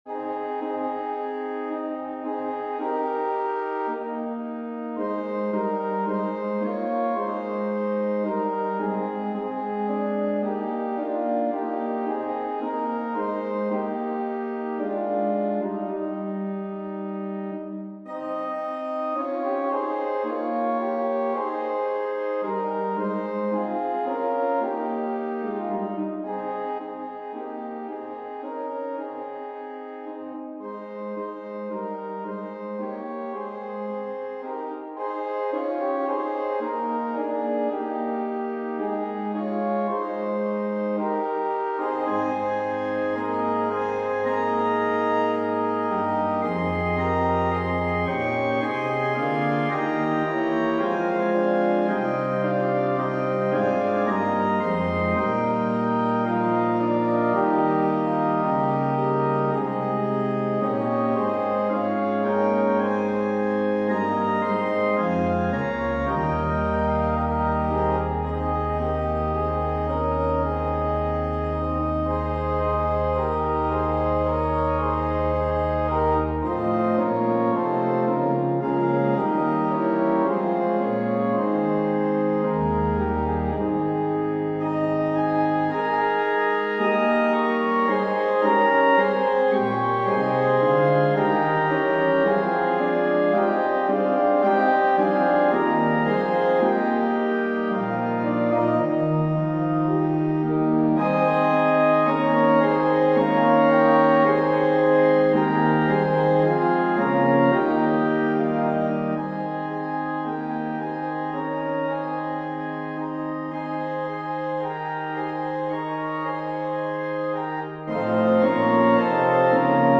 An organ solo version